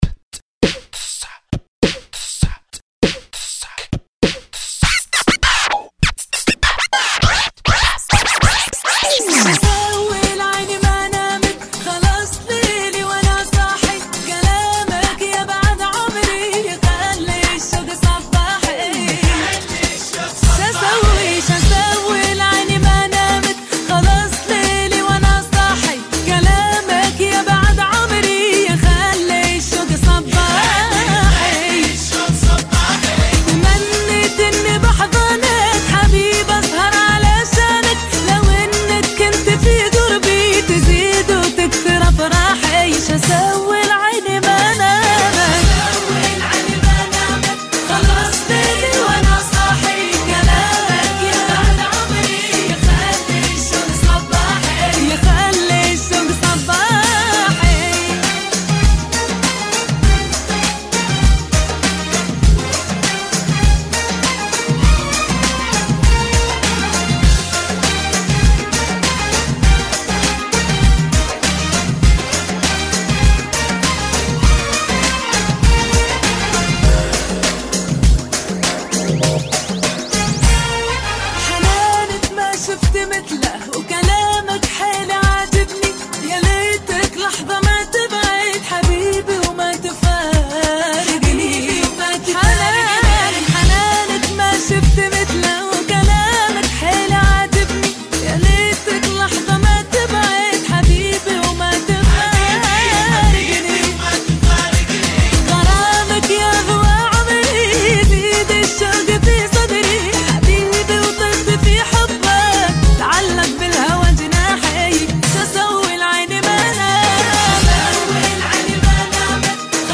(100 BPM)